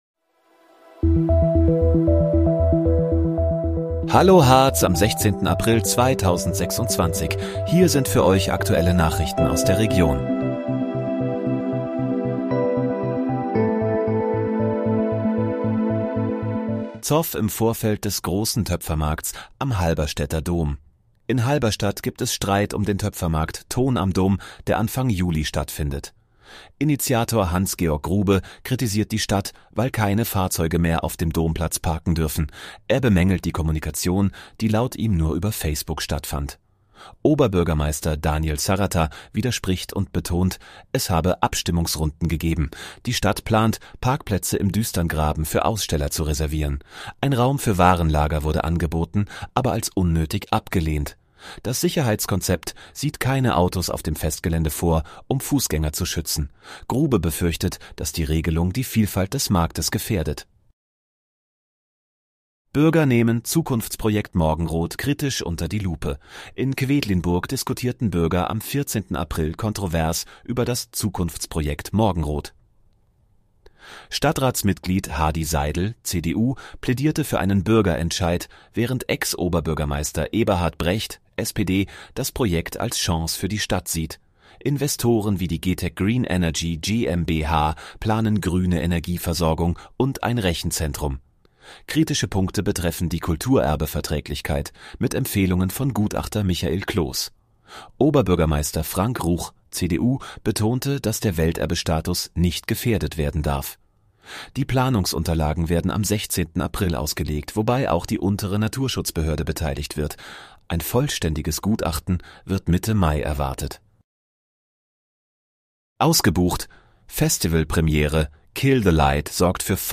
Hallo, Harz: Aktuelle Nachrichten vom 16.04.2026, erstellt mit KI-Unterstützung